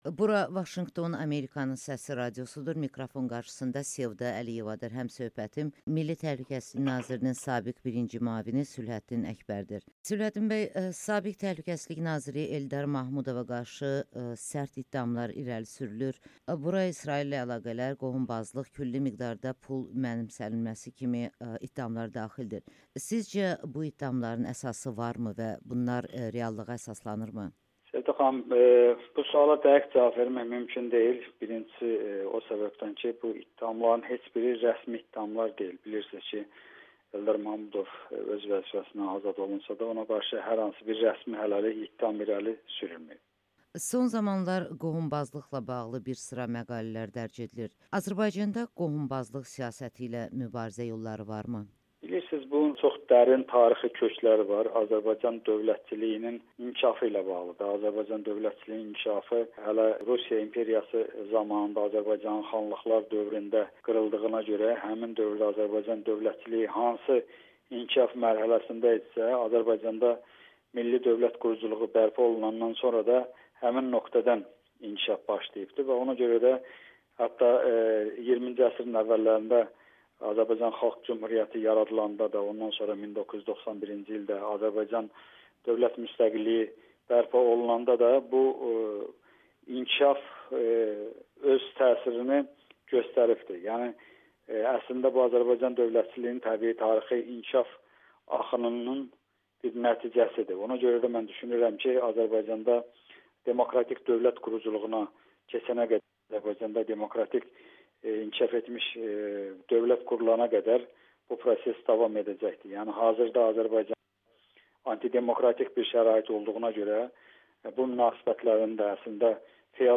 Sülhəddin Əkbər: Azərbaycanda anti-demokratik bir şərait olduğuna görə, feodal münasibətlərin qalması təbiidir [Audio-Müsahibə]